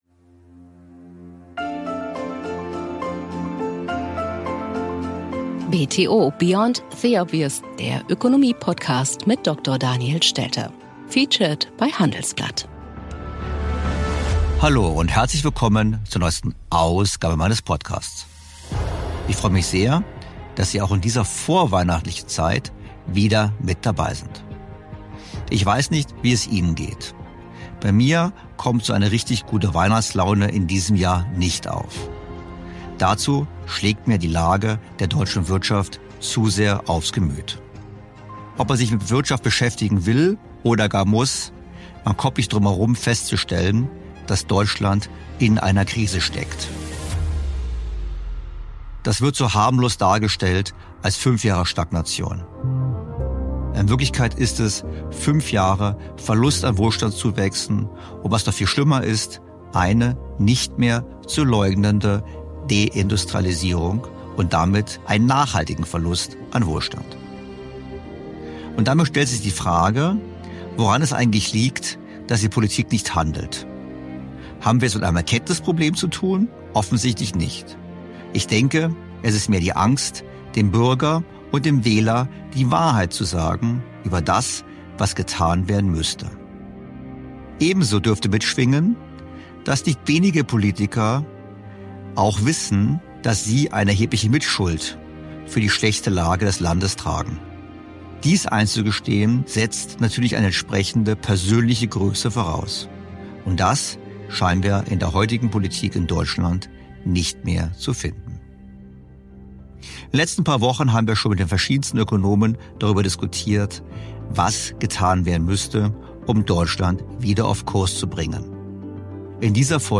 Was zu tun wäre diskutiert Daniel Stelter in dieser Episode mit Prof. Dr. Lars Feld, von 2022 bis 2024 persönlicher Beauftragter des Bundesministers der Finanzen für die gesamtwirtschaftliche Entwicklung.